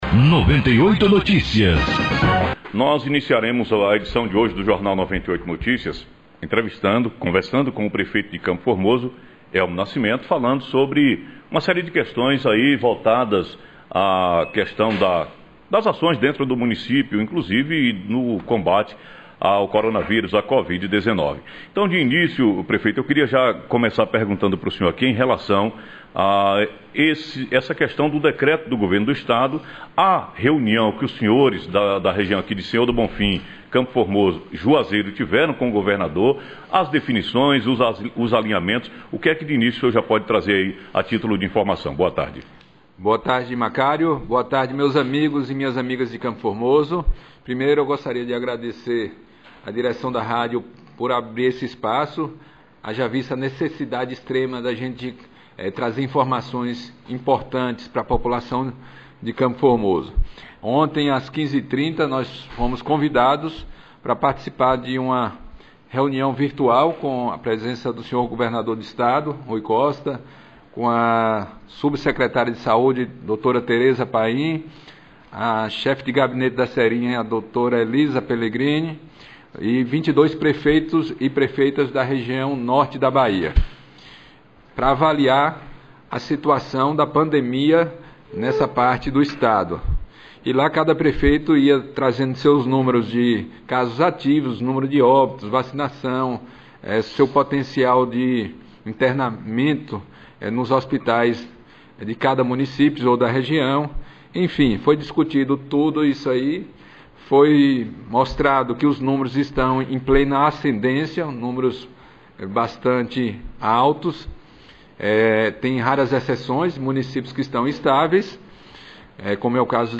Entrevista: Prefeito Elmo Nascimento- Decreto municipal Entrevista